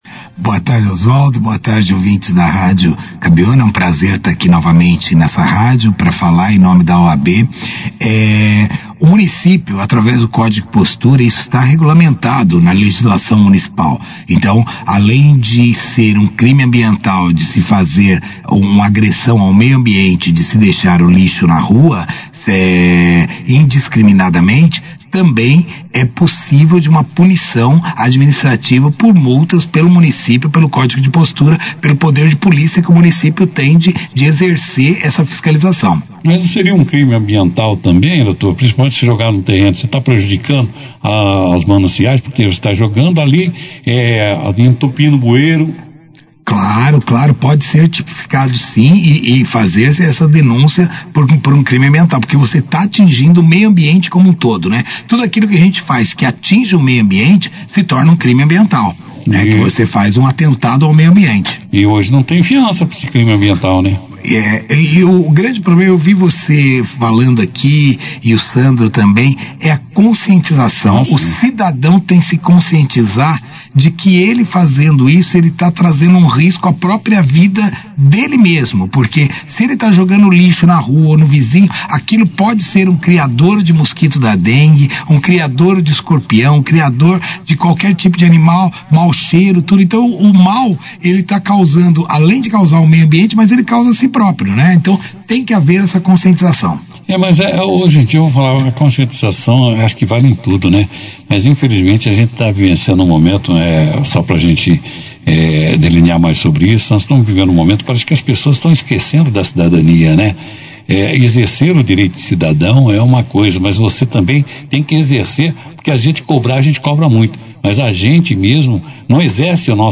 Outros assuntos relacionados à atuação dos magistrados em Bandeirantes também foram discutidos durante a entrevista.